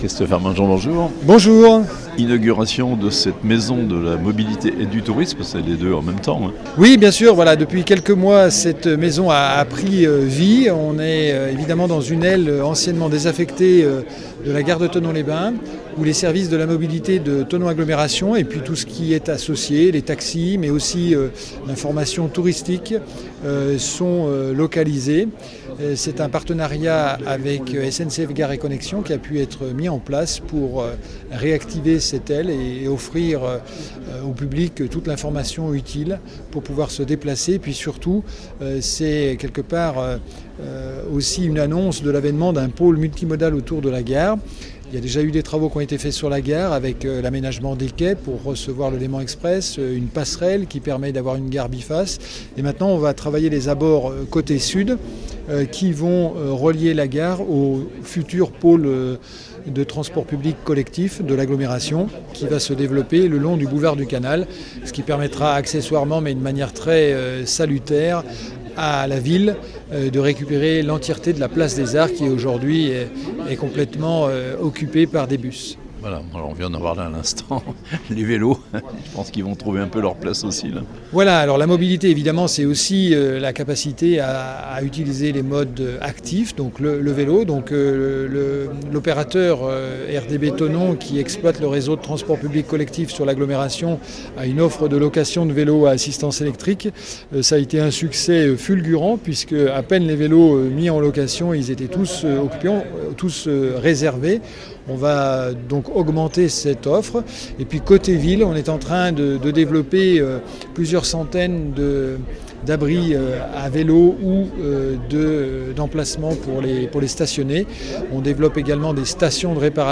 Inauguration de la Maison de la Mobilité à Thonon (interviews)